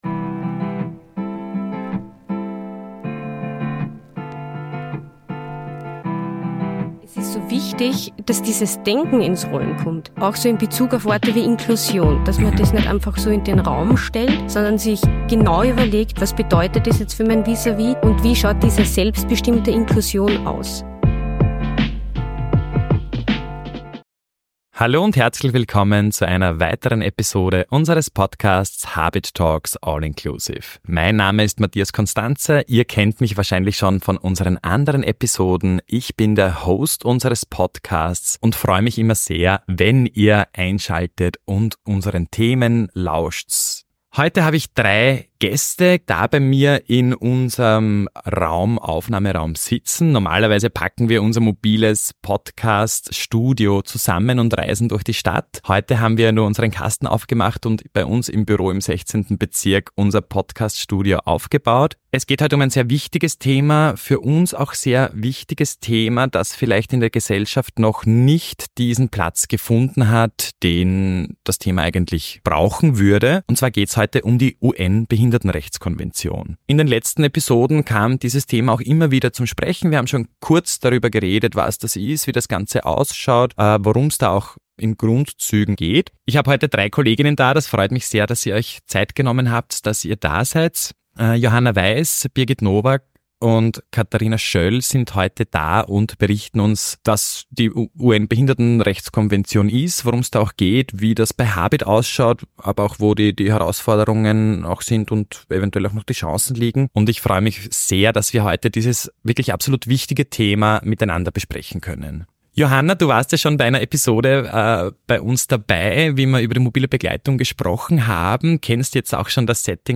Ich habe Rechte | Unsere Arbeitsgruppe zur UN-BRK im Gespräch ~ HABIT Talks: All Inclusive Podcast